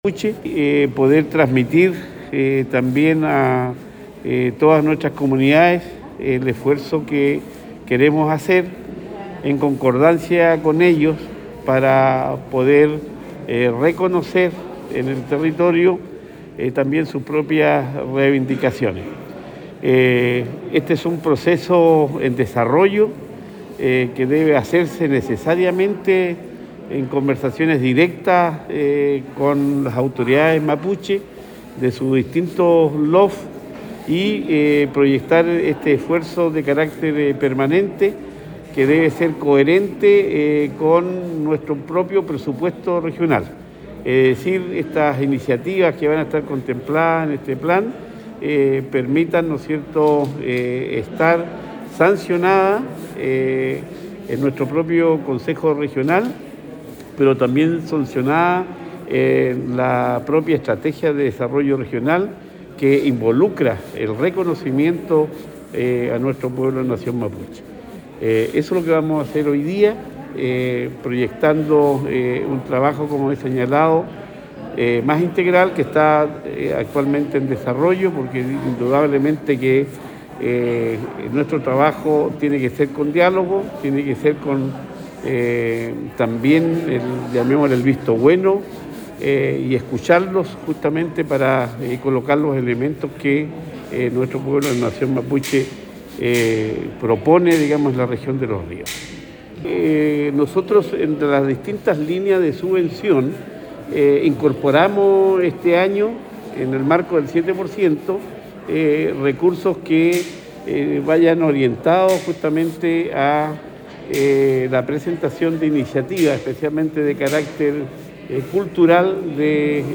El Gobernador Regional, Luis Cuvertino explicó que este inédito fondo regional es parte de una serie de acciones que se enmarcan en un Plan de Desarrollo Integral Indígena, el cual se pretende levantar junto a las comunidades del territorio, con asesoría de la Organización de las Naciones Unidas para la Alimentación y la Agricultura (FAO).
Cuña_Luis-Cuvertino_entrega-recursos-comunidades-.mp3